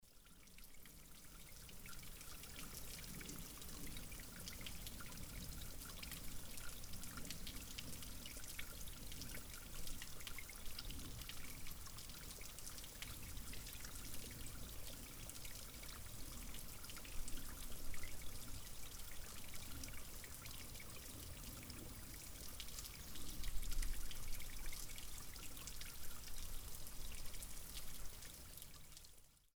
Trickling Stream In Forest Sample